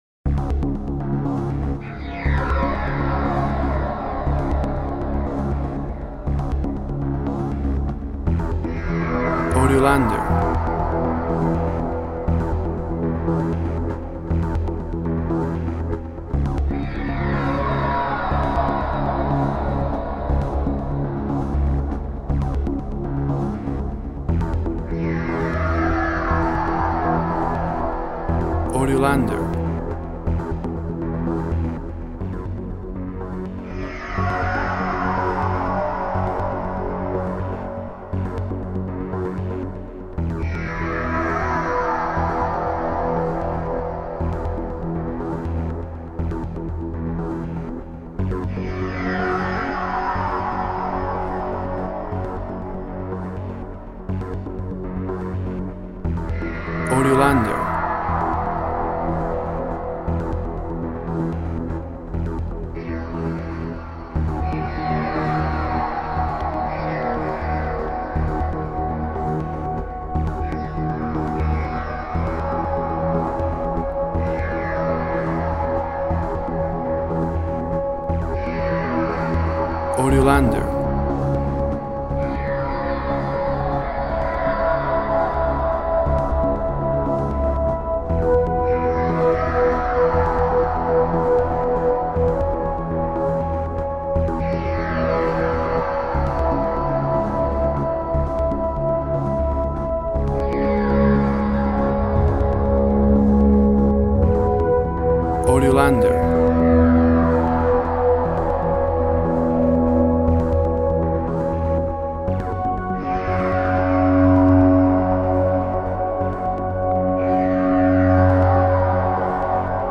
Environmental work with atmospheric sounds.
Tempo (BPM) 95